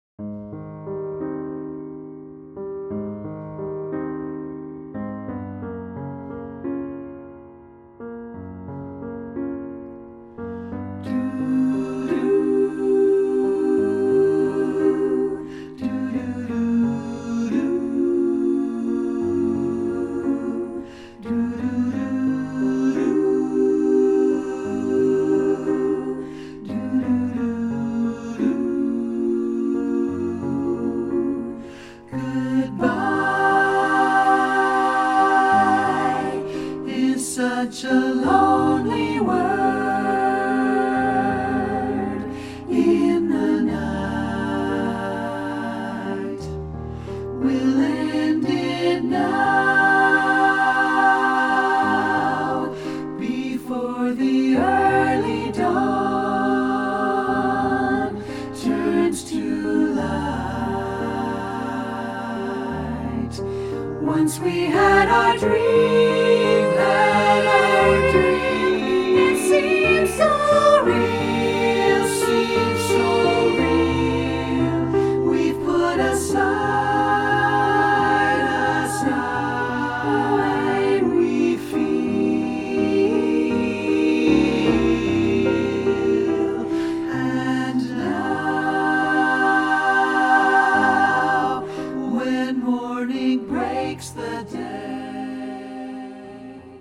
The long awaited release of my iconic ballad